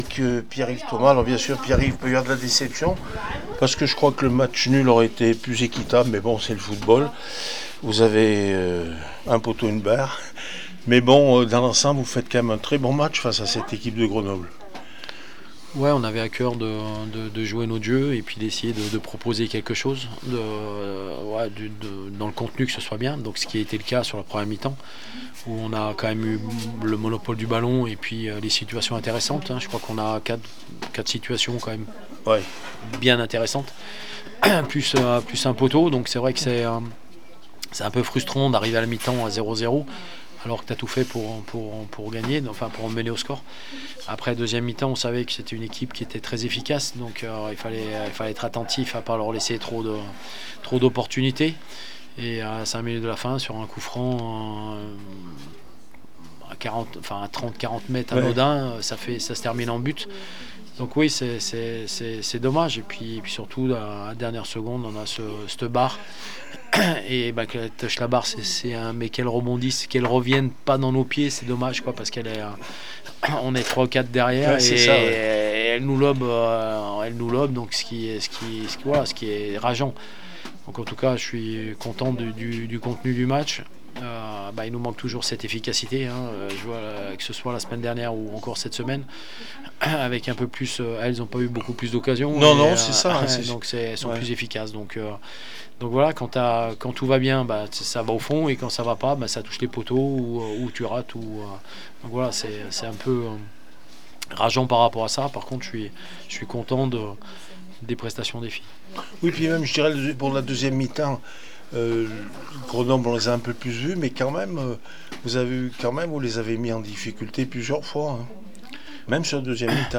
10 mars 2025   1 - Sport, 1 - Vos interviews